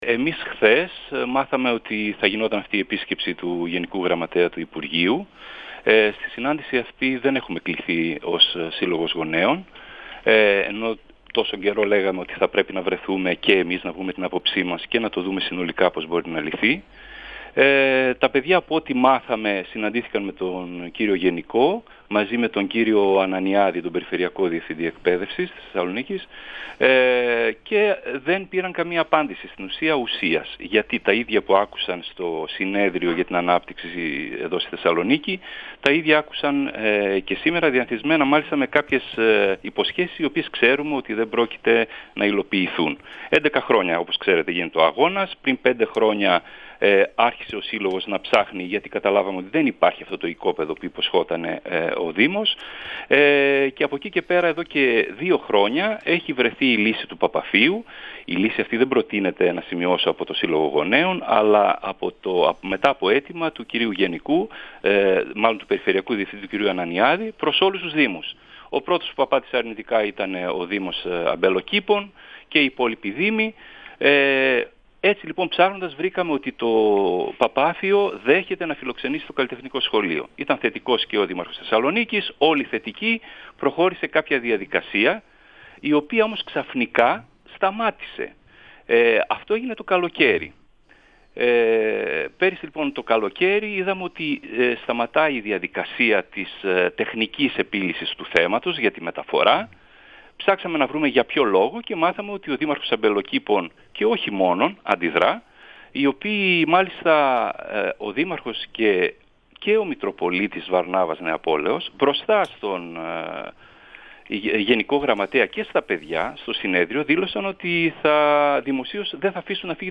Ο γενικός γραμματέας του υπουργείου, Γιώργος Αγγελόπουλος, μιλώντας στον 102FM του Ρ.Σ. Μακεδονίας της ΕΡΤ3, αναφέρθηκε στην επίσκεψη, που πραγματοποίησε την Τετάρτη 25 Απριλίου στους χώρους του Γυμνασίου και του Λυκείου, στο δήμο Αμπελοκήπων – Μενεμένης, χαρακτήρισε την κατάσταση απαράδεκτη και ανέφερε ότι στο τραπέζι του διαλόγου είναι η μεταφορά του Καλλιτεχνικού Σχολείου σε κτίριο στην Περαία ή στο Παπάφειο, καθώς και η ανέγερση νέου σχολικού συγκροτήματος, η οποία όμως θα απαιτήσει πολλά χρόνια.